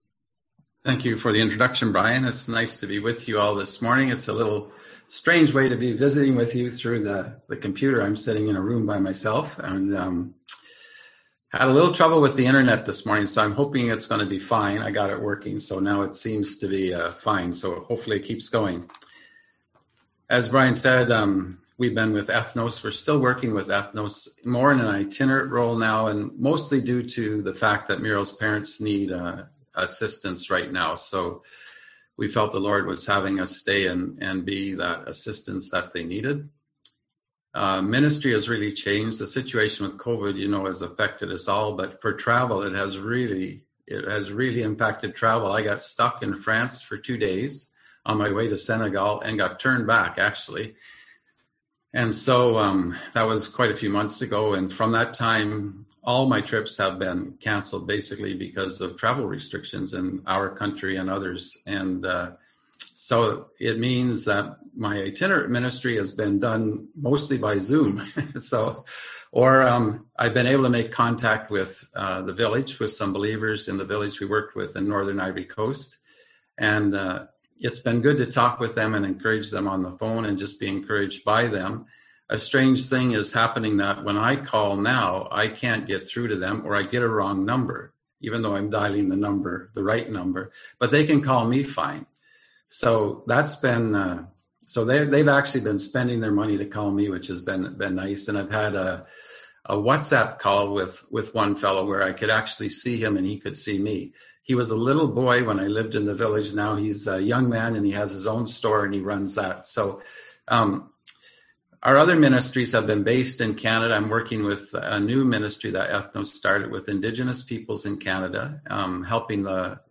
Passage: Proverbs 3:5-6 Service Type: Sunday AM Topics